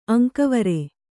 ♪ aŋkavare